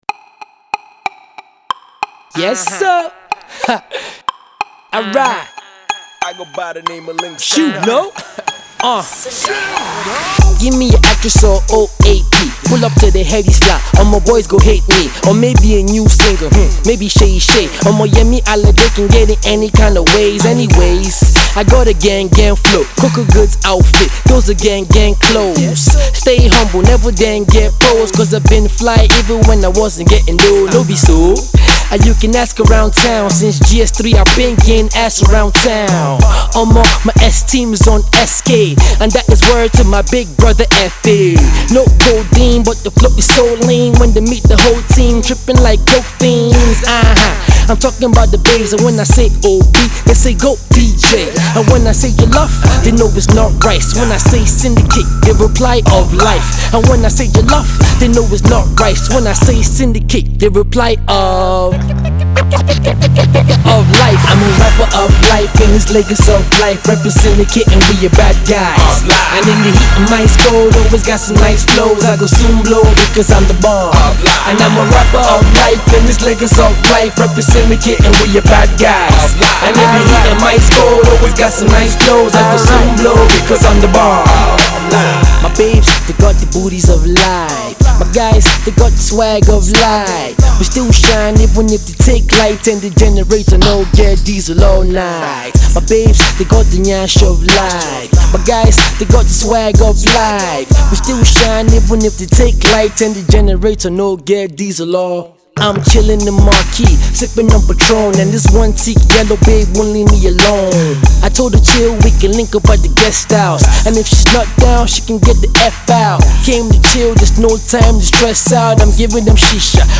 The New Age party track with an infectious bounce
a fun party track with great Lyrical word play